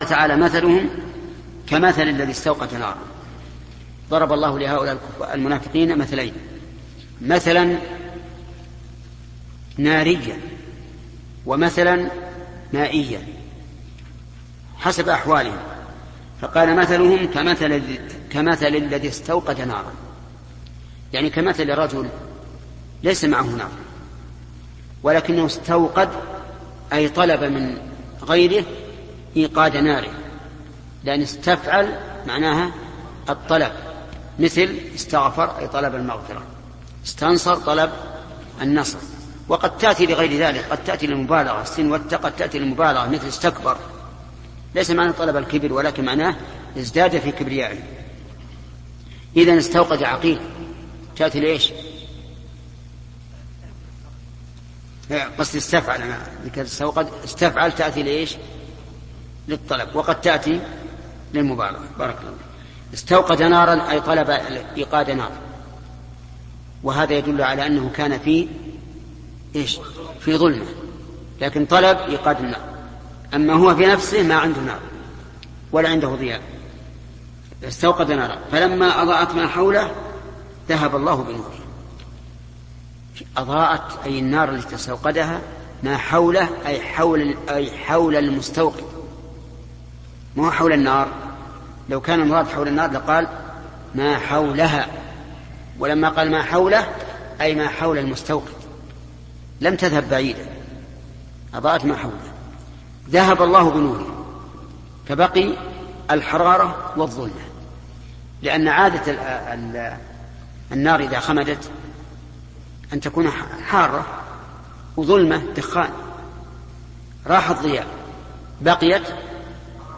📌الشيخ محمد بن صالح العثيمين / تفسير القرآن الكريم